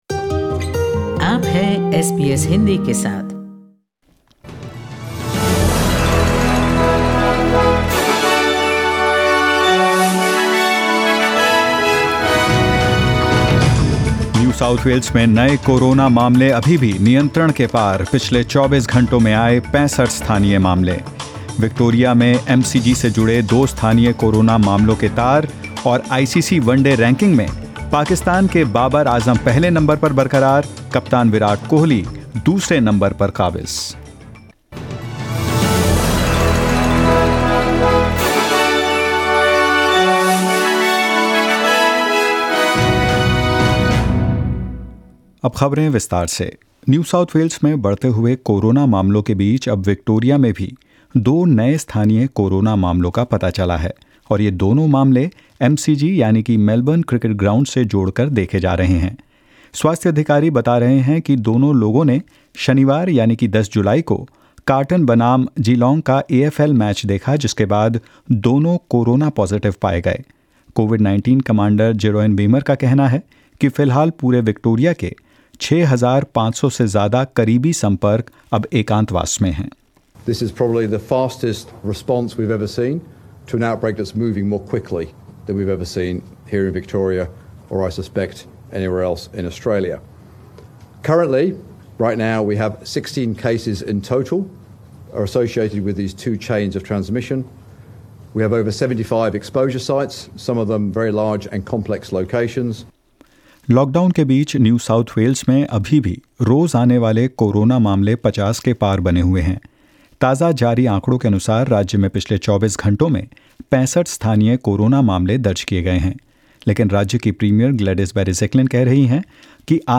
In this latest SBS Hindi News bulletin of Australia and India: Two new COVID-19 cases in Victoria linked to the MCG; Virat Kohli, Rohit Sharma in Top 3 as Pakistan’s Babar Azam continues to top ODI rankings, and more.